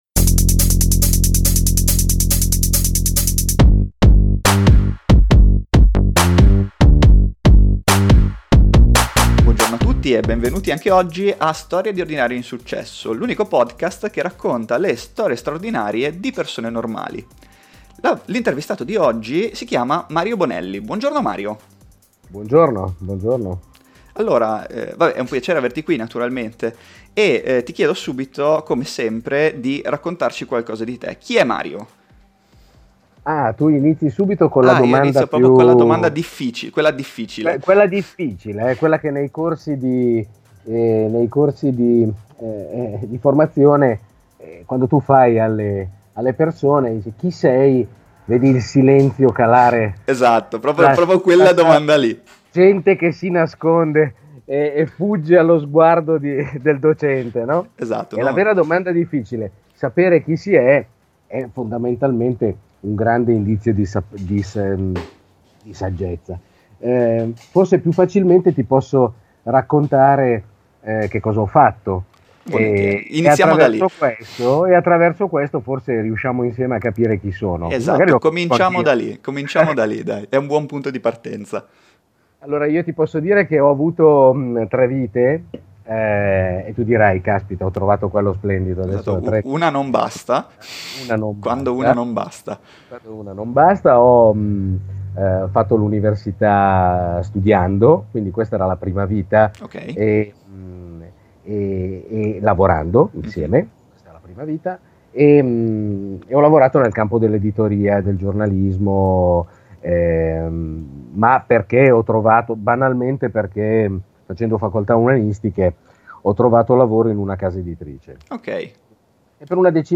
Qui sotto il podcast dell’intervista!: